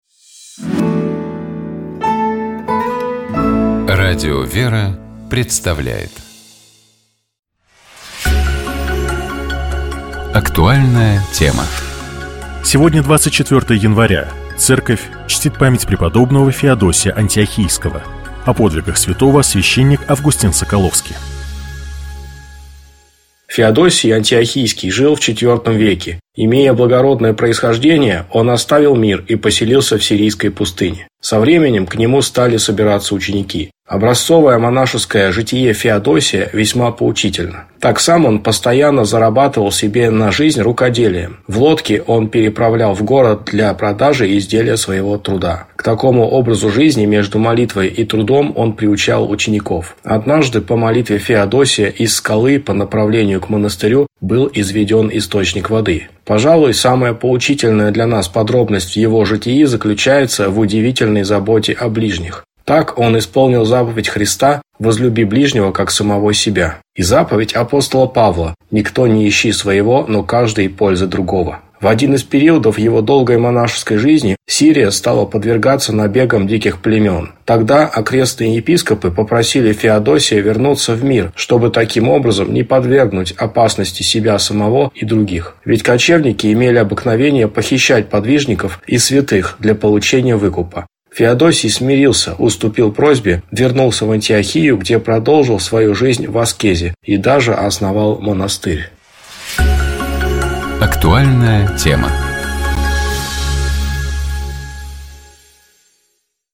О подвигах святого, — священник